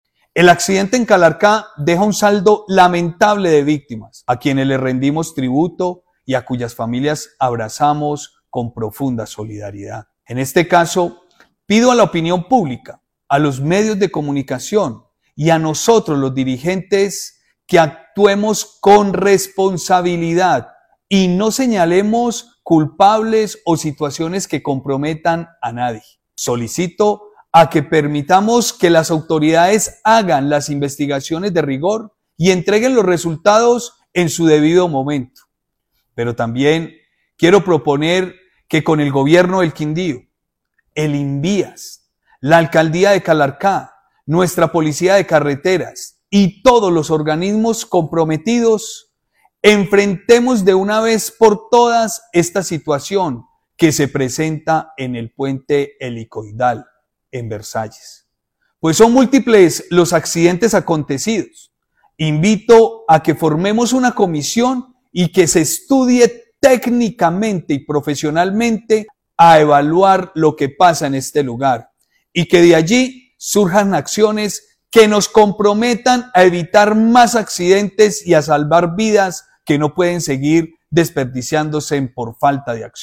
Juan Miguel Galvis, gobernador del Quindío